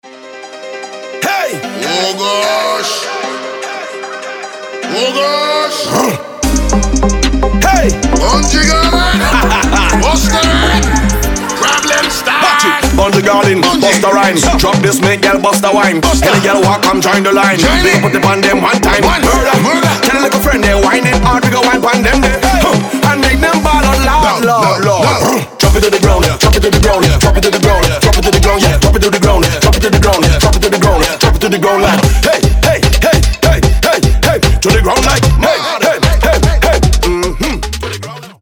• Качество: 320, Stereo
веселые
заводные
dancehall
энергичные
быстрые
soca
Afrodance
Стиль: soca, reggae, dancehall, afrobeat